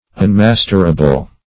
Search Result for " unmasterable" : The Collaborative International Dictionary of English v.0.48: Unmasterable \Un*mas"ter*a*ble\, a. Incapable of being mastered or subdued.